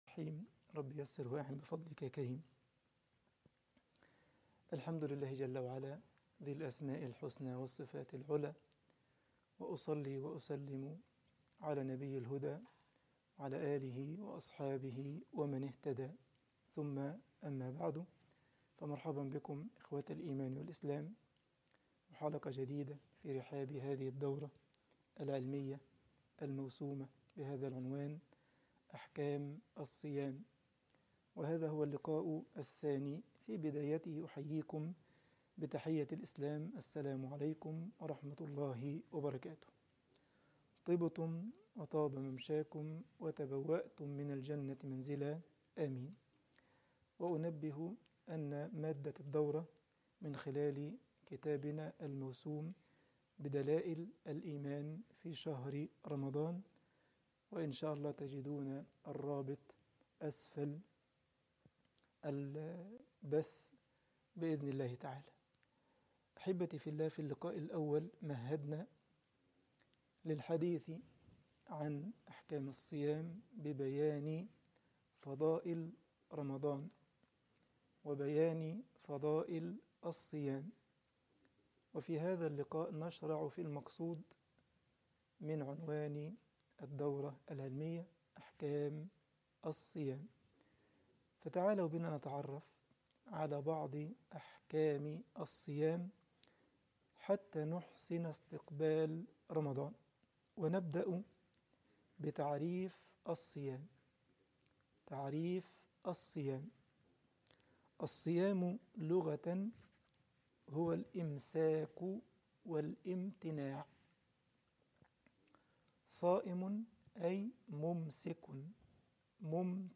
الدورة العلمية رقم 1 أحكام الصيام المحاضرة رقم 2